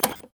UI_labelSelect.ogg